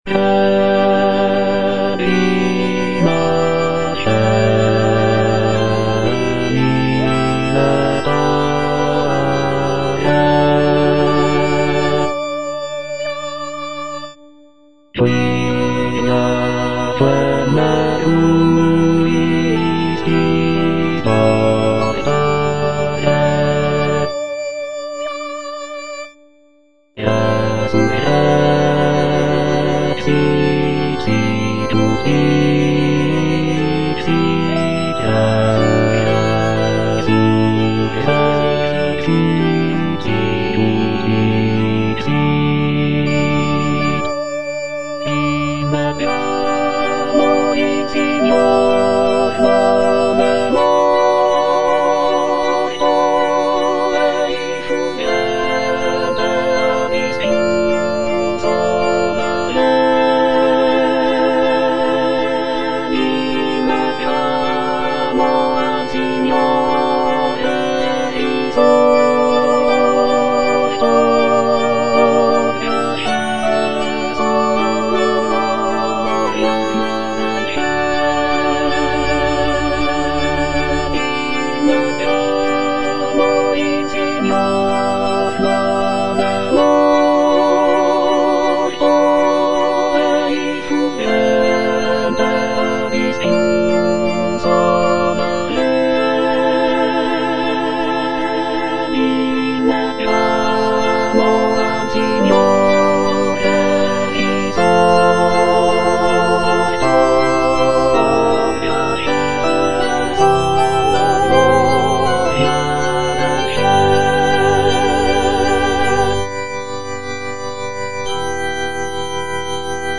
P. MASCAGNI - CHOIRS FROM "CAVALLERIA RUSTICANA" Regina coeli (internal choir, bass) (Emphasised voice and other voices) Ads stop: